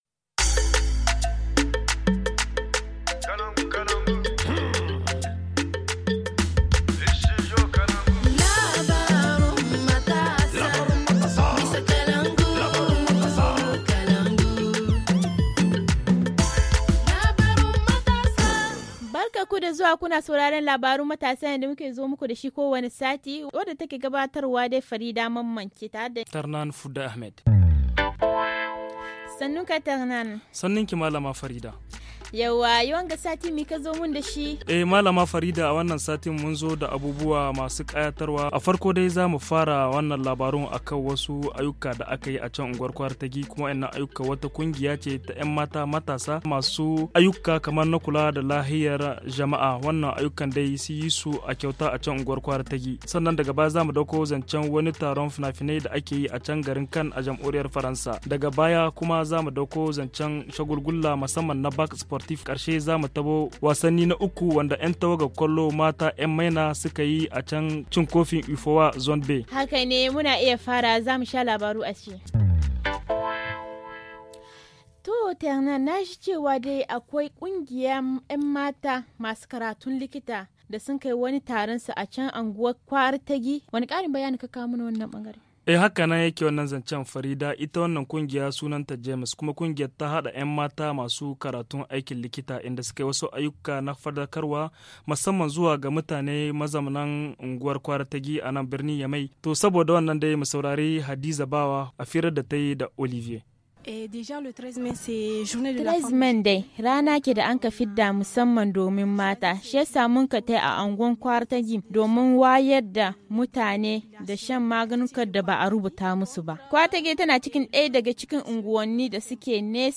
Il s’agit d’un journal parlé hebdomadaire d’une durée de huit minutes, en cinq langues : haoussa, zarma, fulfulde, tamasheq et français.